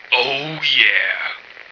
flak_m/sounds/male1/int/M1ohyeah.ogg at 46d7a67f3b5e08d8f919e45ef4a95ee923b4048b
M1ohyeah.ogg